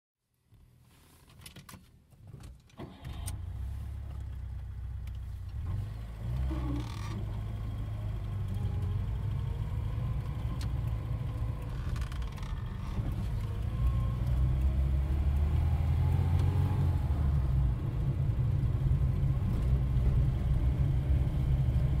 Звук езды на Жигулях Копейка по городским улицам запись из салона